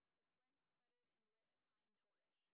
sp29_street_snr10.wav